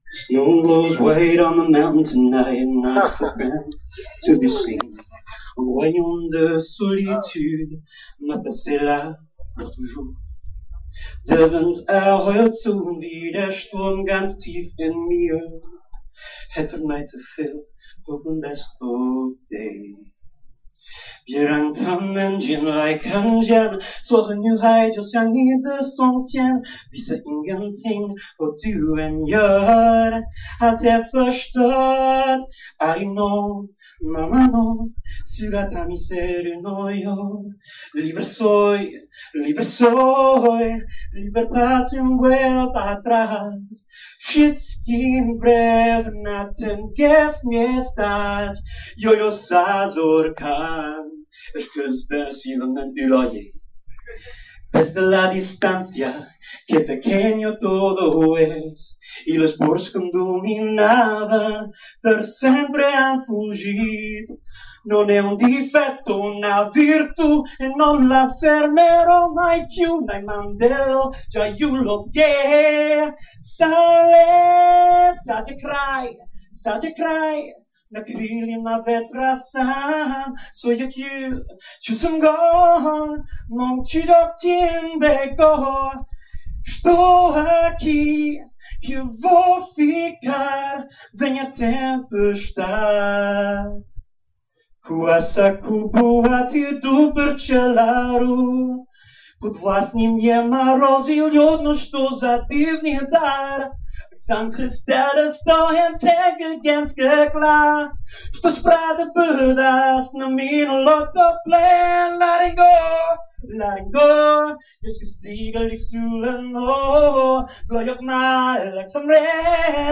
Here’s a song in multiple languages